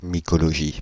Ääntäminen
Synonyymit mycétologie Ääntäminen France (Île-de-France): IPA: /mi.kɔ.lɔ.ʒi/ Haettu sana löytyi näillä lähdekielillä: ranska Käännös Substantiivit 1. микология Suku: f .